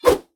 combat / weapons / default_swingable / fire2.ogg
fire2.ogg